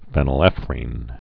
(fĕnəl-ĕfrēn, fēnəl-)